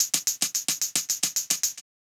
Index of /musicradar/ultimate-hihat-samples/110bpm
UHH_ElectroHatD_110-01.wav